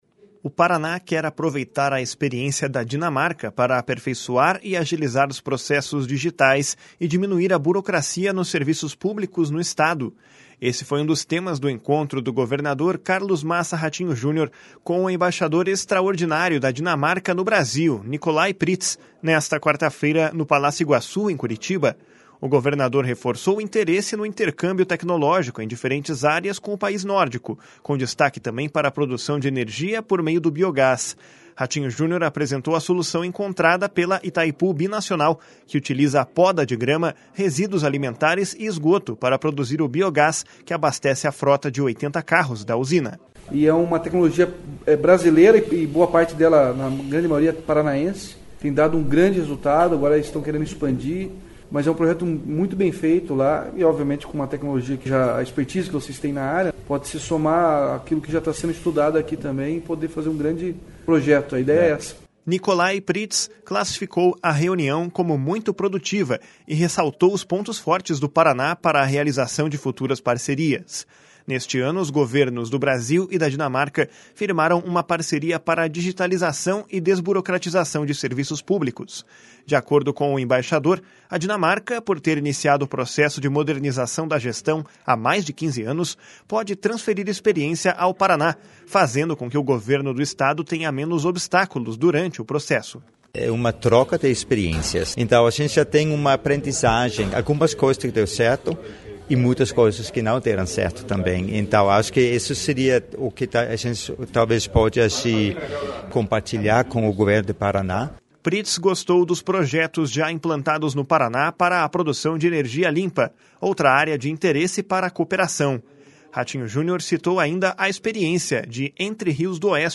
// SONORA RATINHO JUNIOR //
// SONORA NICOLAI PRYTZ //